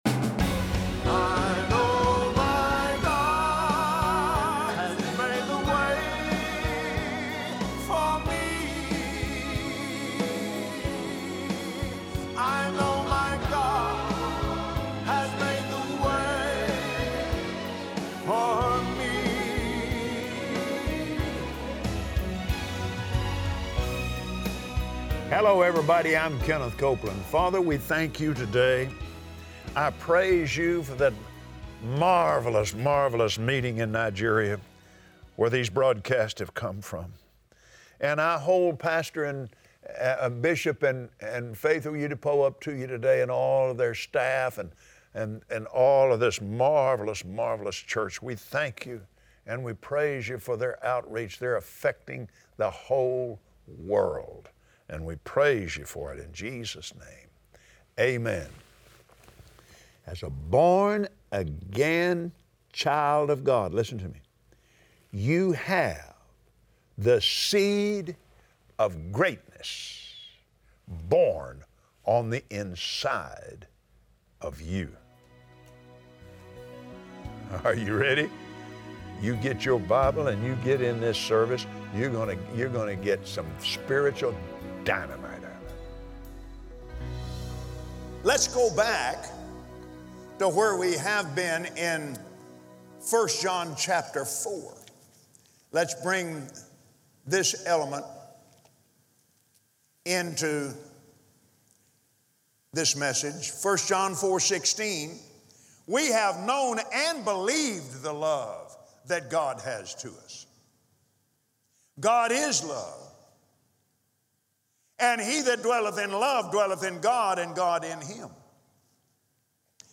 Believers Voice of Victory Audio Broadcast for Wednesday 09/06/2017 Believing the love of God is not a head decision—it’s a heart decision! Listen to Kenneth Copeland on Believer’s Voice of Victory explain how having faith in God’s love is the foundation of a life built to last.